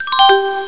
"Ta Da" sound ).
OK1_Beep.wav